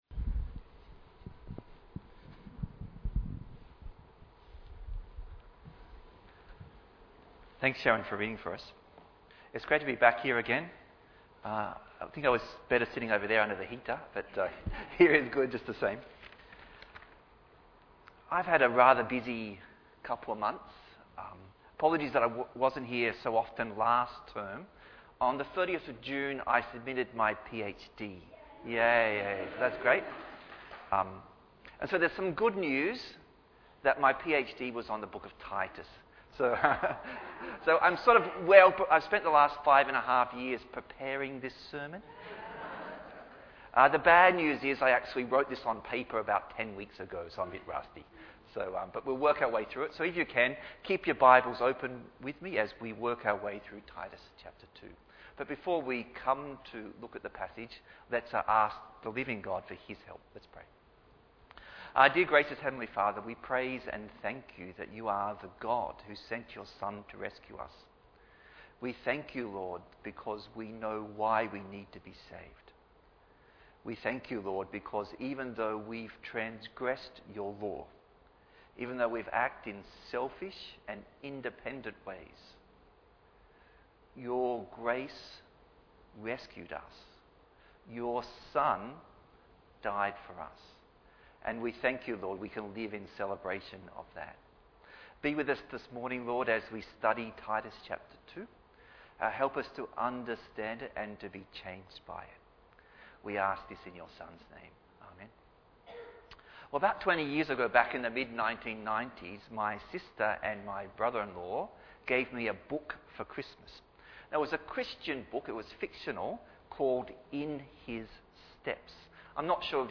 Bible Text: Titus 2 | Preacher